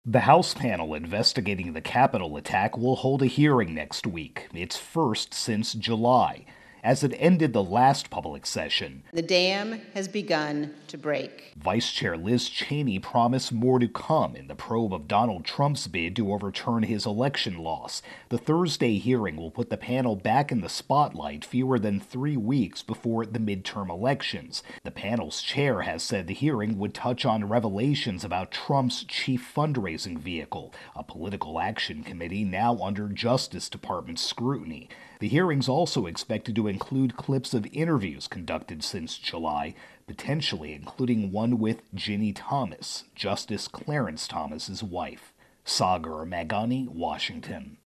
reports on Capitol Riot-Investigation.